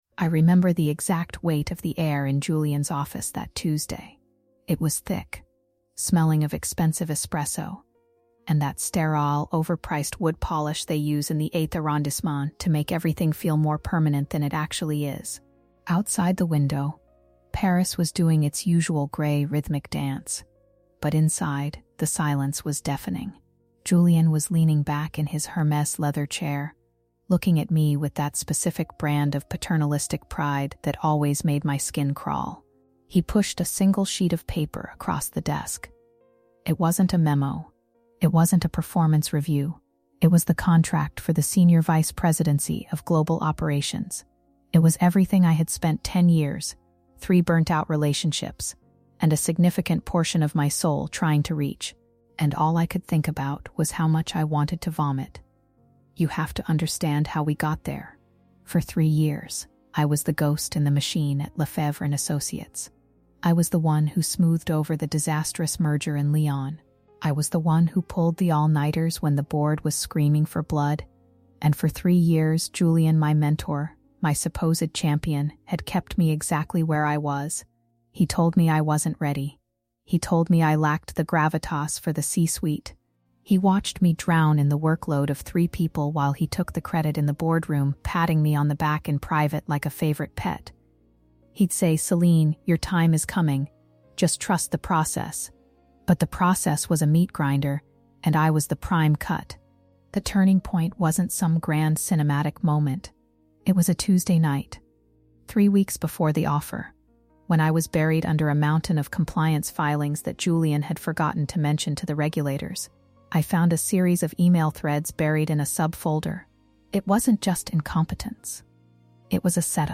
This is a gritty, first-person account of power struggles, financial scandals, and the ultimate price of integrity in an industry designed to strip it away.